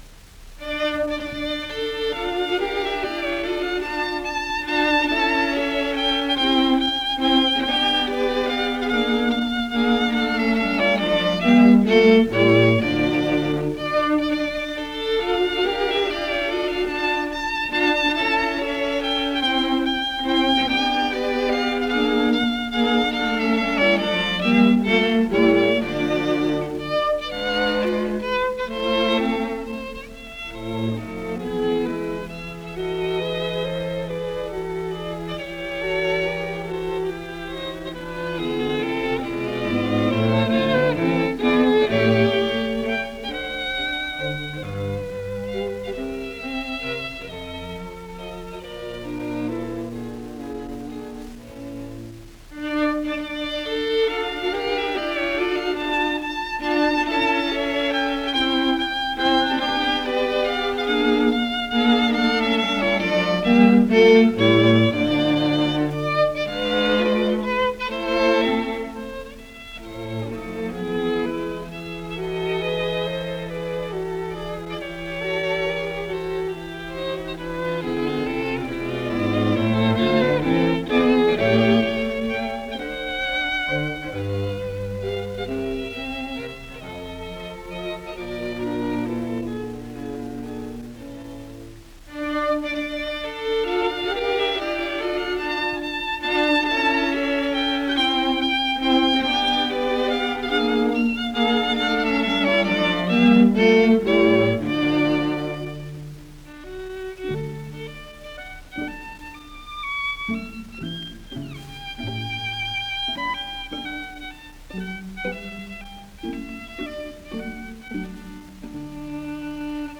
String Quartet No. 15 (Mozart)